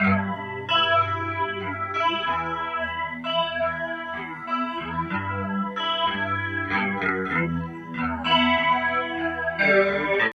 29 GUIT 3 -R.wav